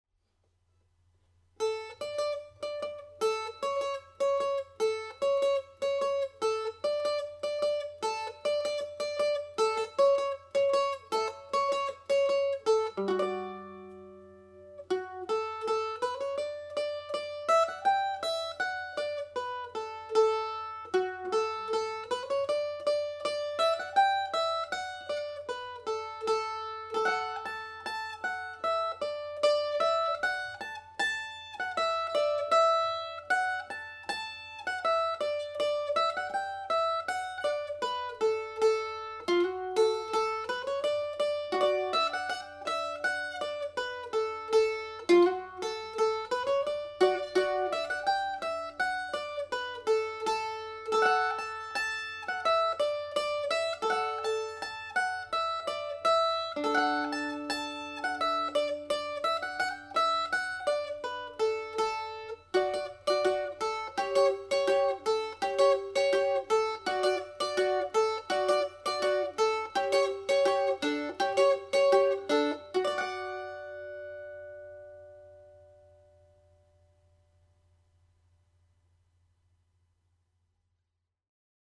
Polka (D Major)
Spanish Lady played at normal speed